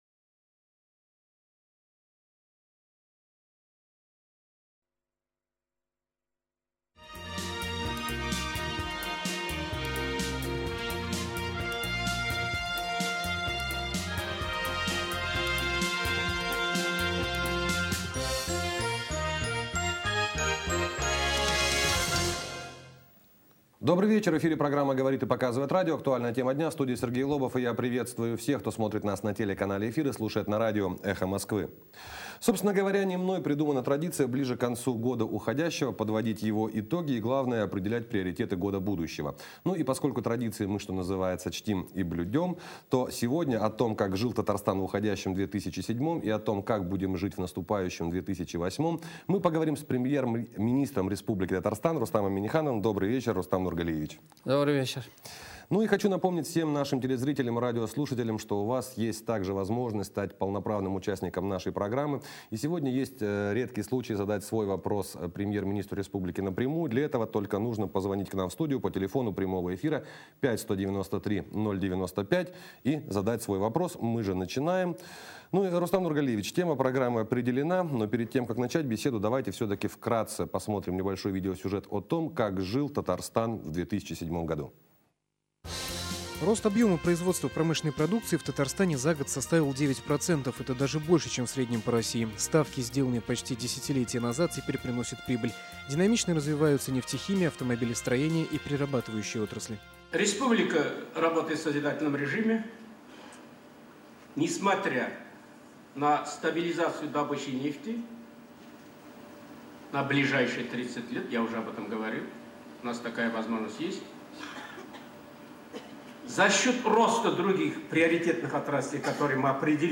Аудиорепортаж
Выступление Премьер-министра Республики Татарстан Р.Н.Минниханова в прямом эфире программы «ЭХО Москвы в Казани» Канал: Выступление Премьер-министра Республики Татарстан Р.Н.Минниханова в прямом эфире программы «ЭХО Москвы в Казани» 1 Скачать аудиоролик